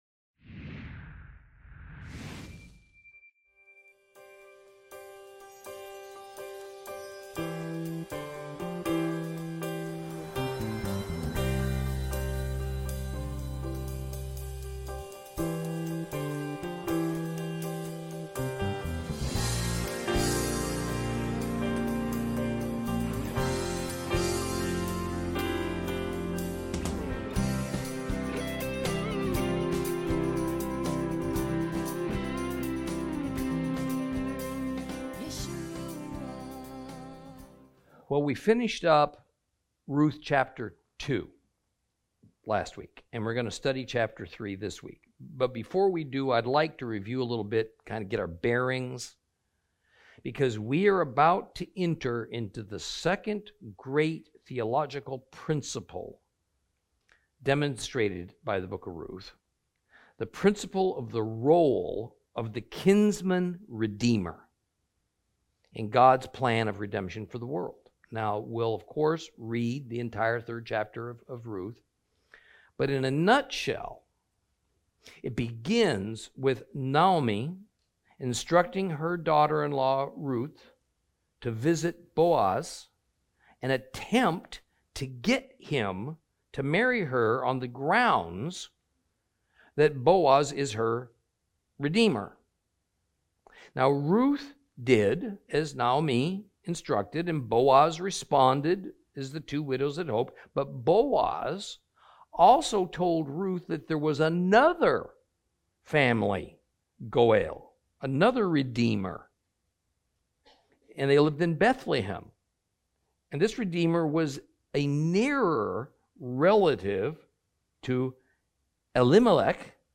Lesson 6 Ch3 - Torah Class